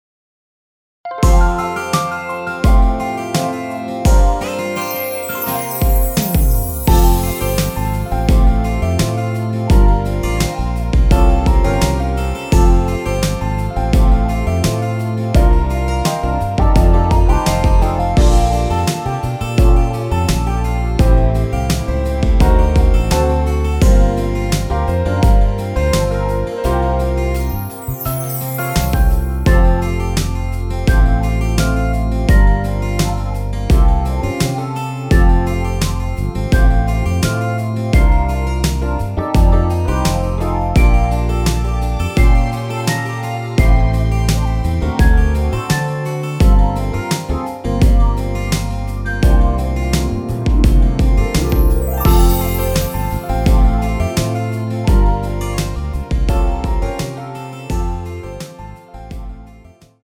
음정은 반음정씩 변하게 되며 노래방도 마찬가지로 반음정씩 변하게 됩니다.
앞부분30초, 뒷부분30초씩 편집해서 올려 드리고 있습니다.
중간에 음이 끈어지고 다시 나오는 이유는
곡명 옆 (-1)은 반음 내림, (+1)은 반음 올림 입니다.